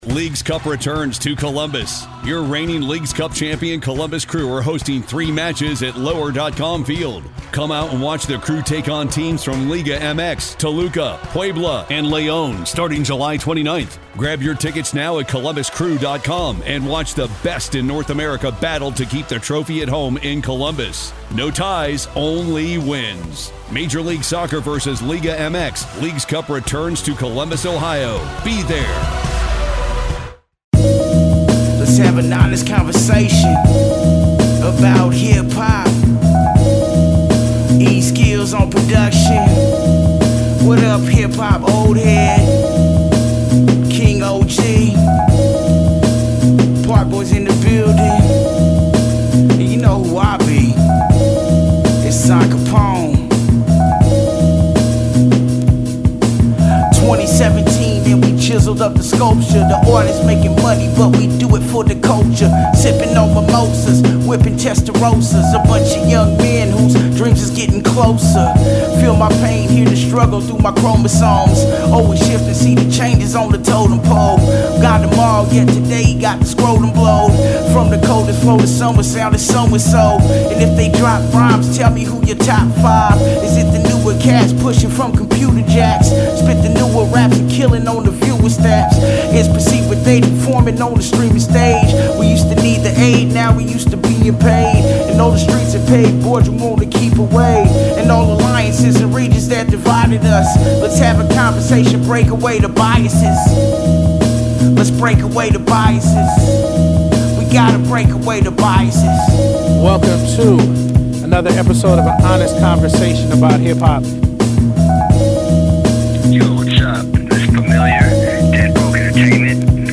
Recorded at Big City Musiz and Entertainment